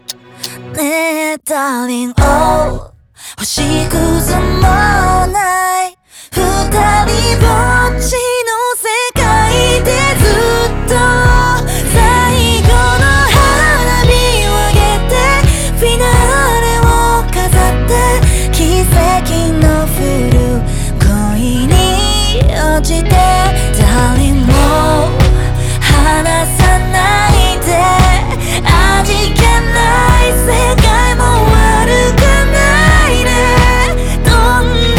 Жанр: J-pop / Поп